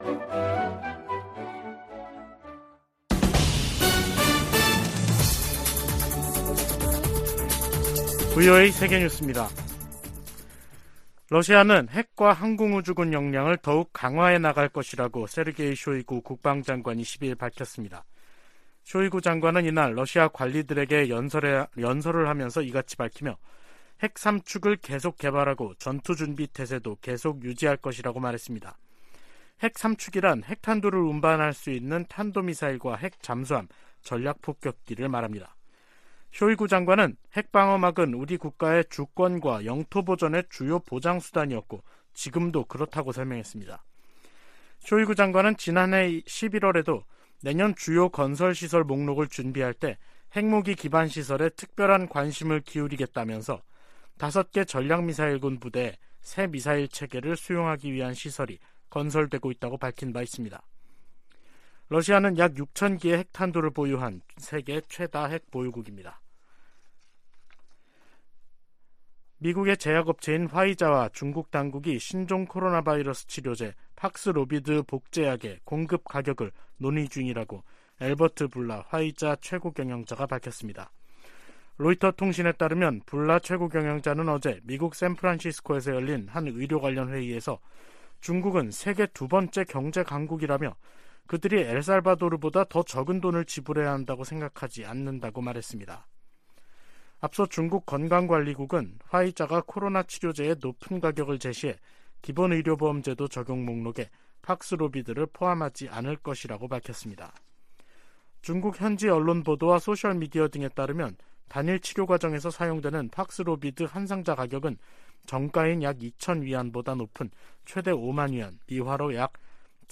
VOA 한국어 간판 뉴스 프로그램 '뉴스 투데이', 2023년 1월 10일 3부 방송입니다. 미 국무부는 방한 중인 국무부 경제 차관이 미국의 인플레이션 감축법(IRA)에 대한 한국의 우려에 관해 논의할 것이라고 밝혔습니다. 한국 군 당국은 대북 확성기 방송 재개 방안을 검토하고 있는 것으로 알려졌습니다.